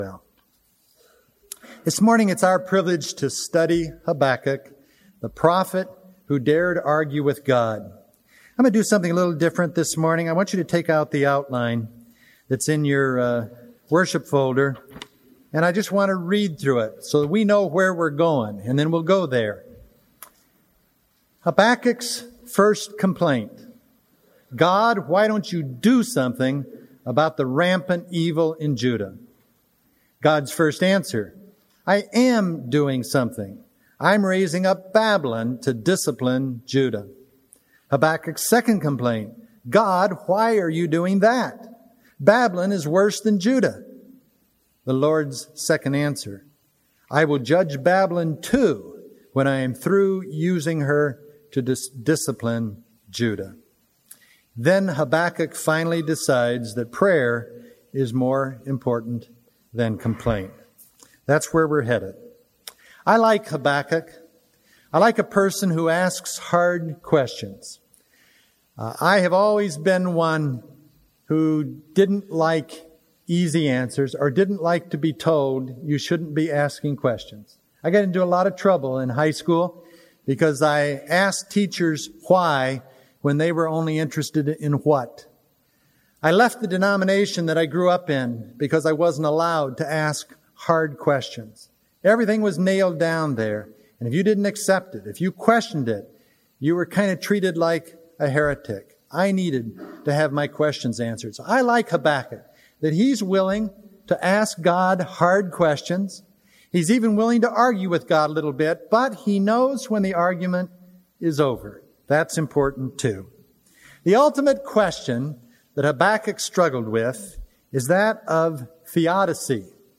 Note : This series, done in the summer of 1999, involved one sermon each on the Twelve Minor Prophets.